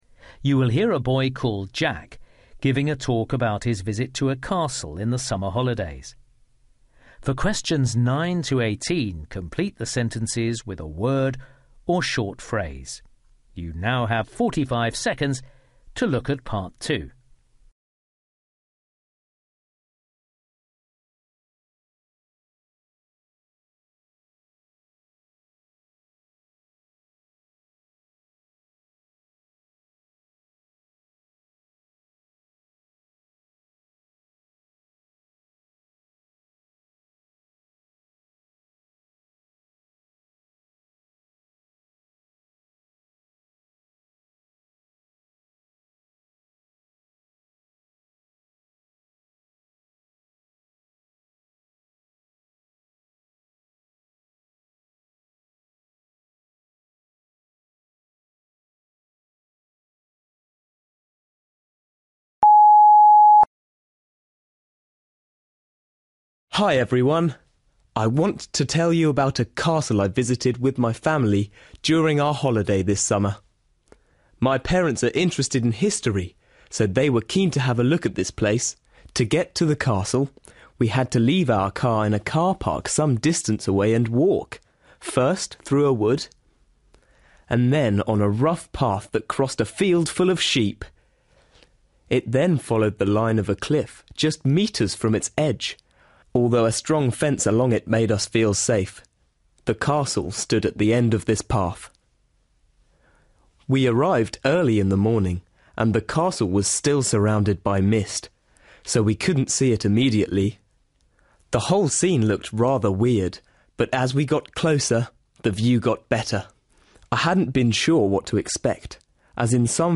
You will hear a boy called Jack giving a talk about his visit to a castle in the summer holidays.